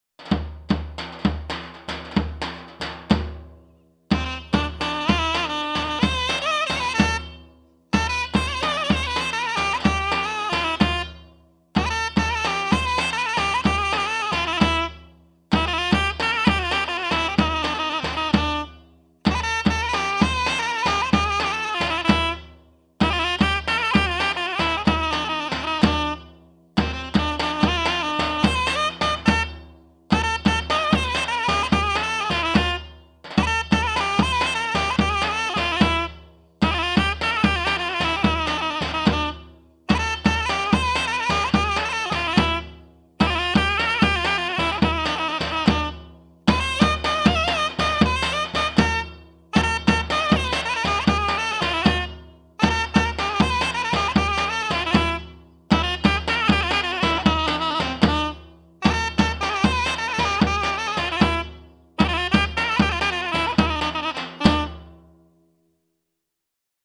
Kategori Sözlü, Sözsüz Yöresel Müzikler